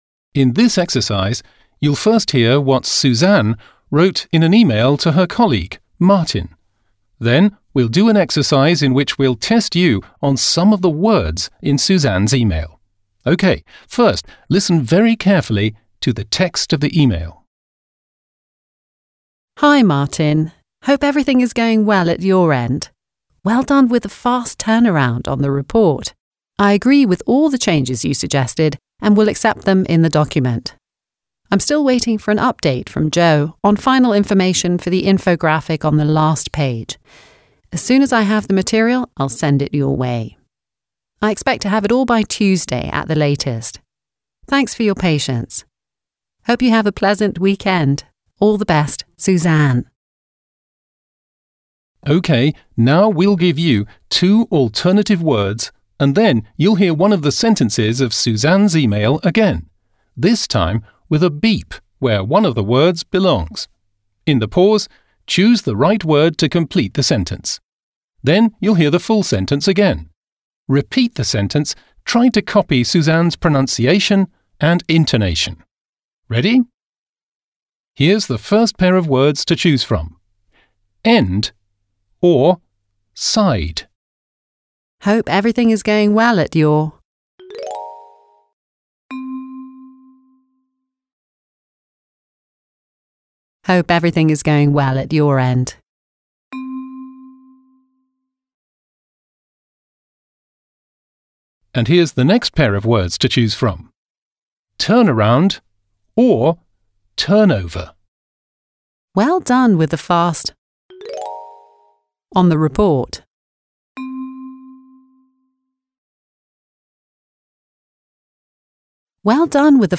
Audio-Übung
Audio-Trainer